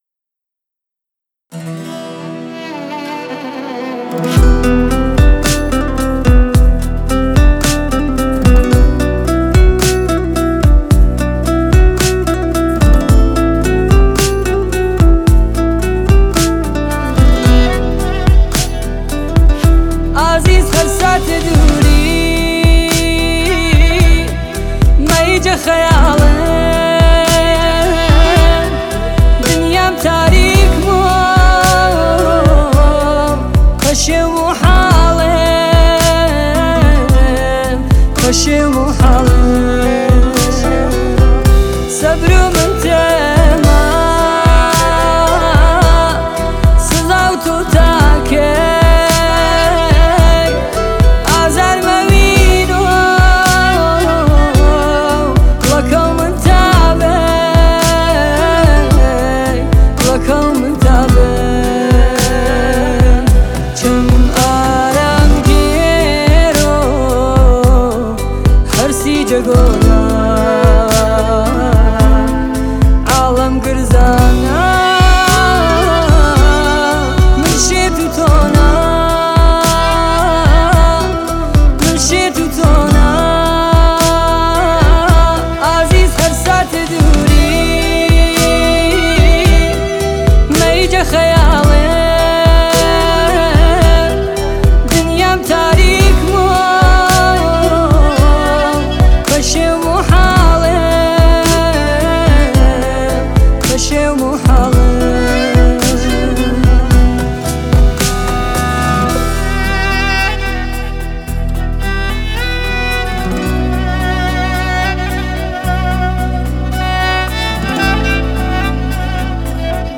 آهنگ کوردی
آهنگ با صدای زن